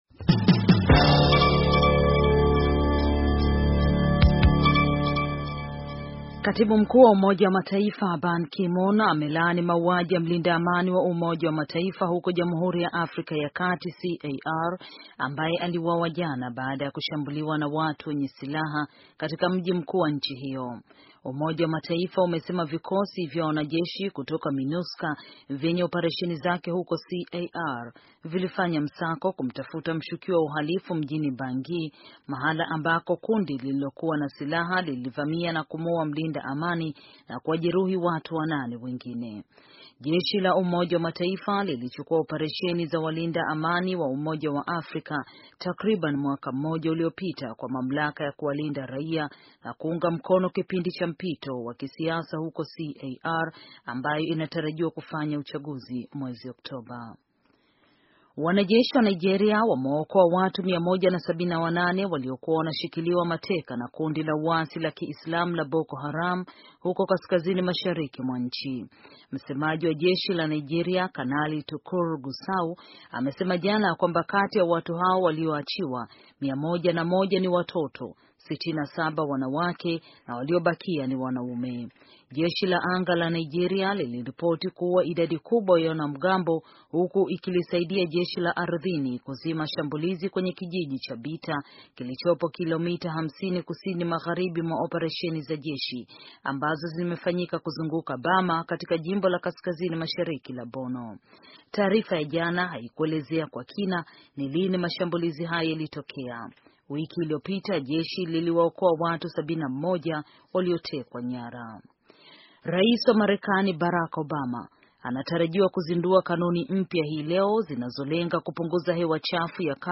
Taarifa ya habari - 5:12